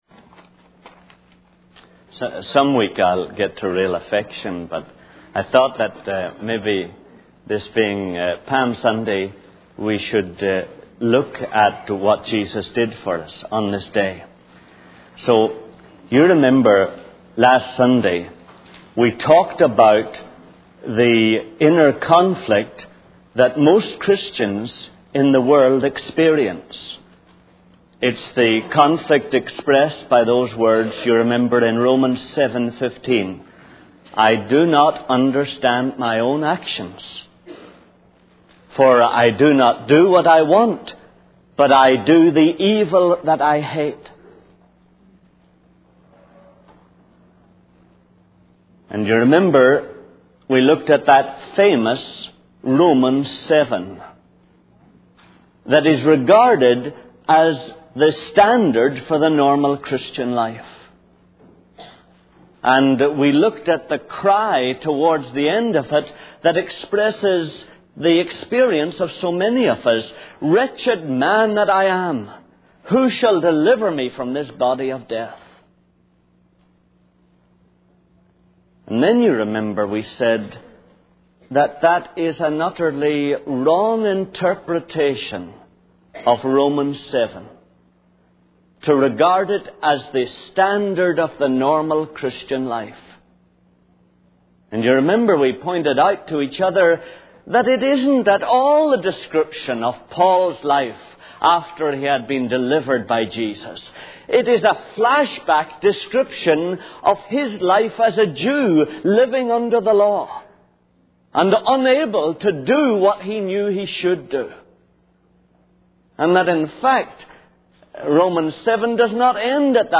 In this sermon, the speaker reflects on the inner conflict that many Christians experience, as described in Romans 7:15. He challenges the common interpretation that this passage represents the normal Christian life, arguing that it is actually a description of Paul's life before he was delivered by Jesus. The speaker emphasizes that through Jesus, believers are delivered from a life of moral struggle and sin.